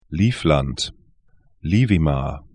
Livland 'li:flant Liivimaa 'li:vima: et